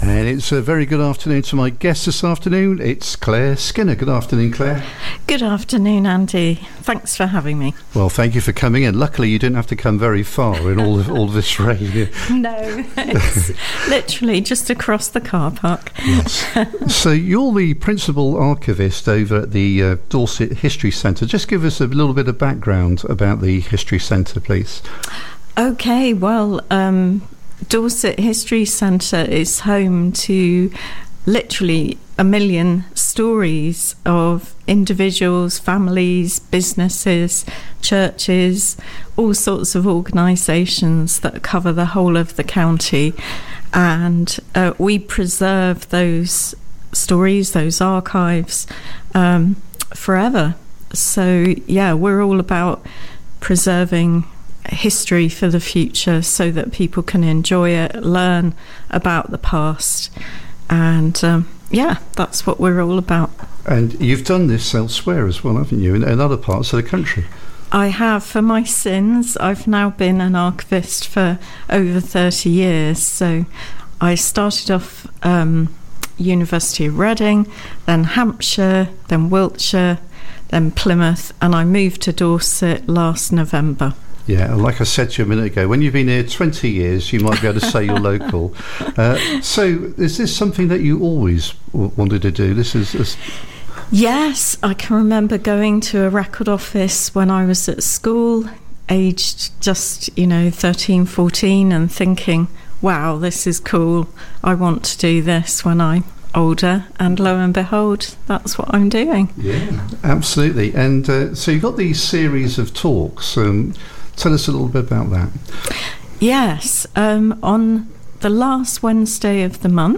The Community Radio Station covering Central-Southern Dorset, run by volunteers and not-for-profit